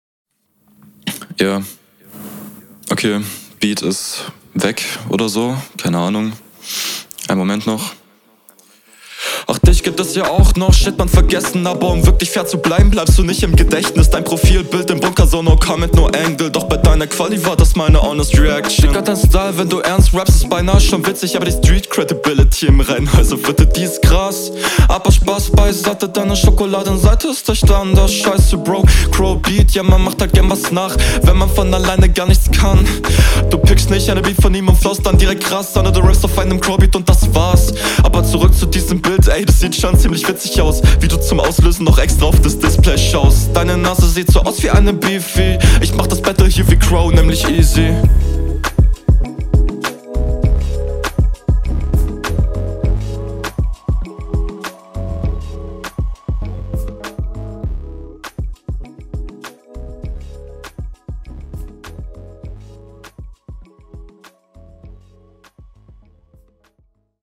mixing sehr cool attidude auch gut lines sehr simple passiert nicht viel flow auch geil …